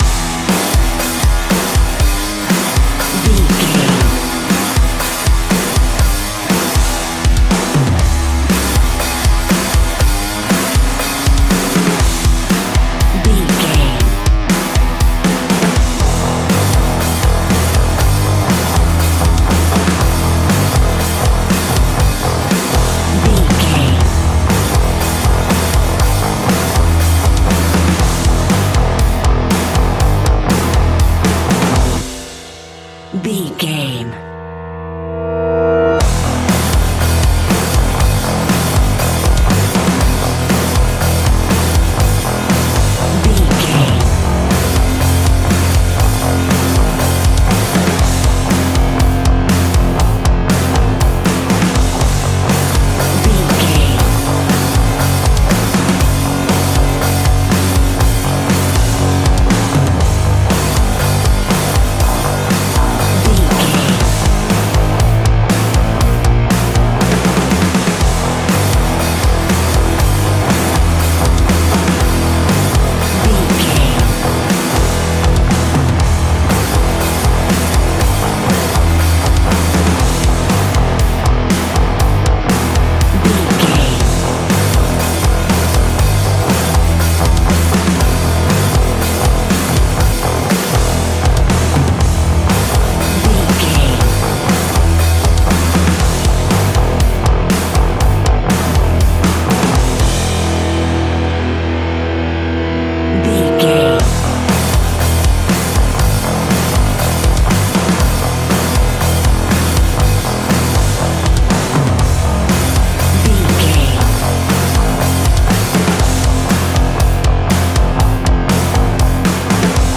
Ionian/Major
E♭
heavy rock
heavy metal
instrumentals